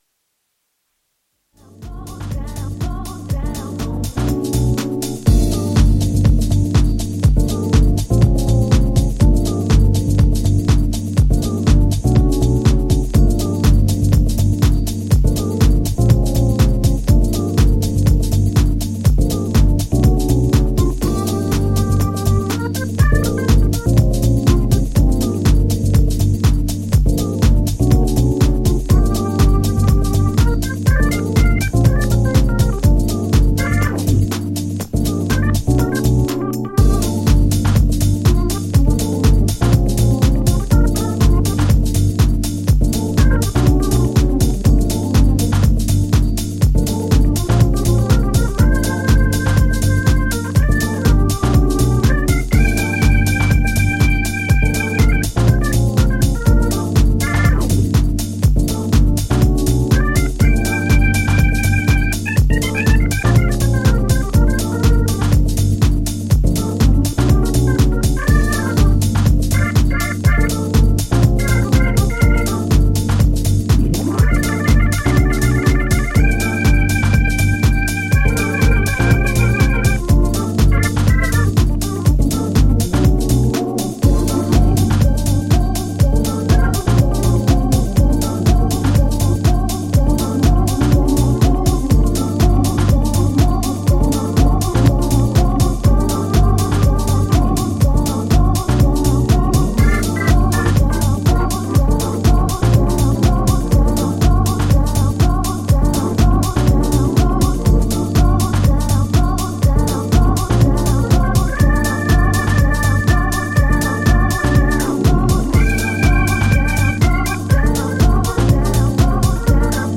VOCAL DUB
ジャンル(スタイル) SOULFUL HOUSE / JAZZY HOUSE